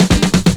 DRUMFILL04-L.wav